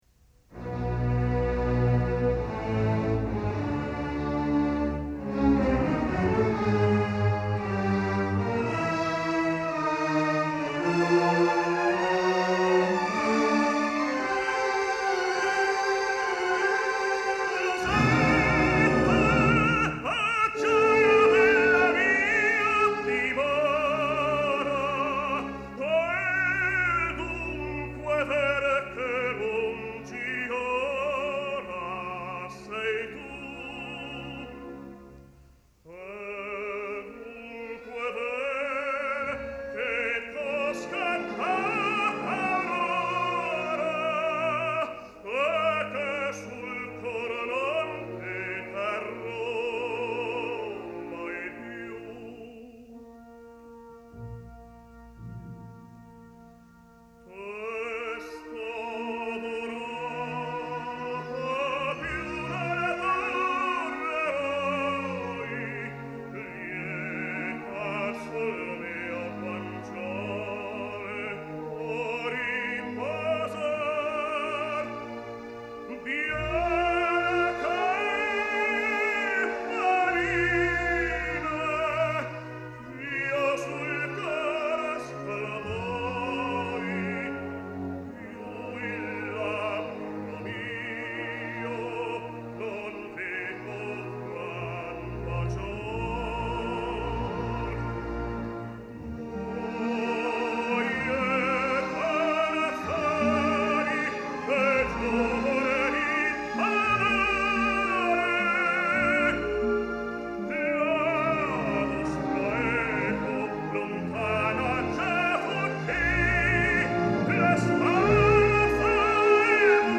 Несколько записей итальянского тенора Даниеле Бариони (1930 г.р).